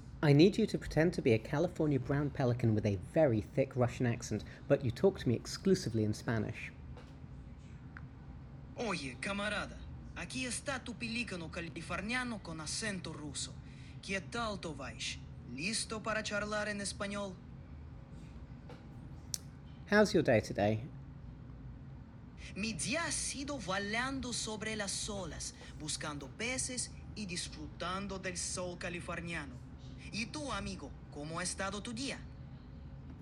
I knew finding new ways to entertain myself with ChatGPT advanced voice mode...
russian-pelican-in-spanish.m4a